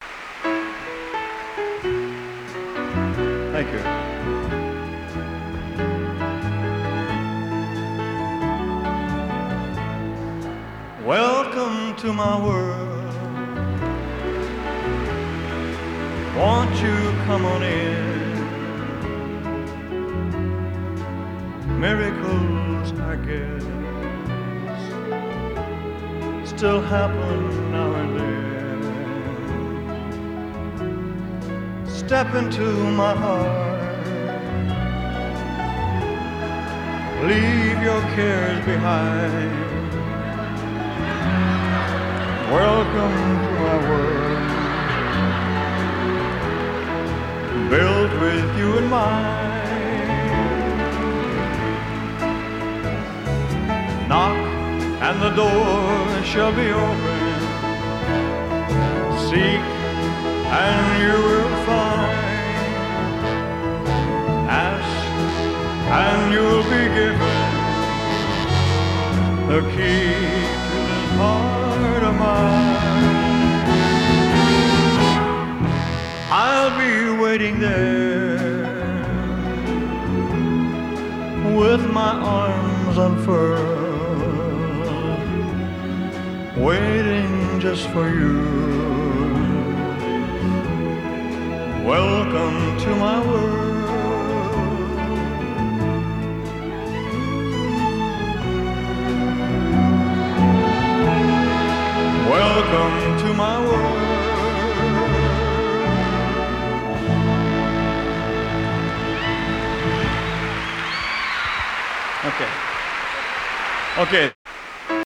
balada